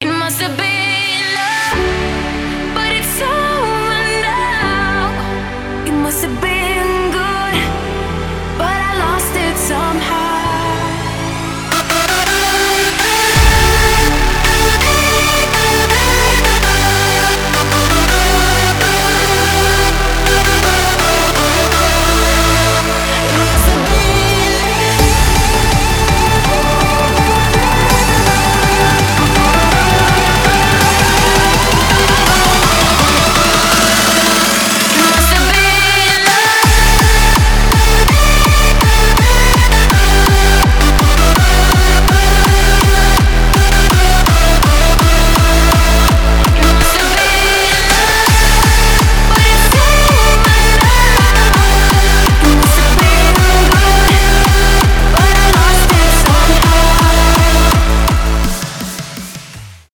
клубные
hardstyle